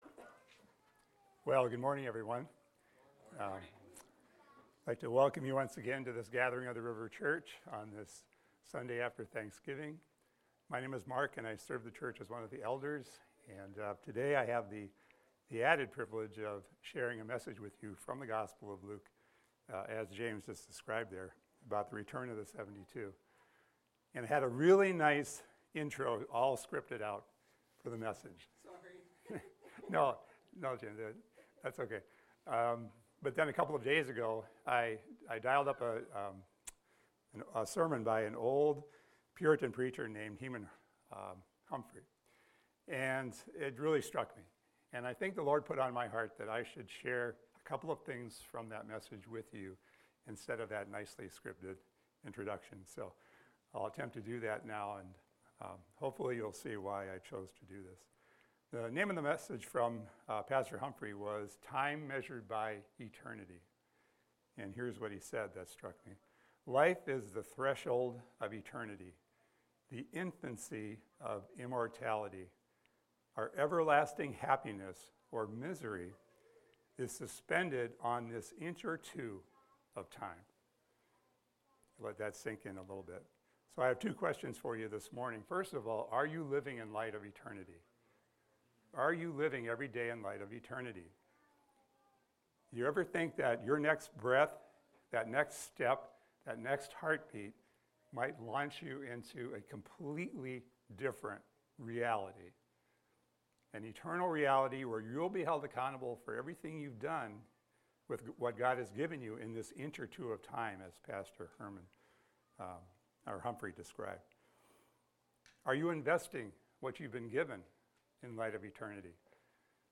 This is a recording of a sermon titled, "Luke 10:17-24."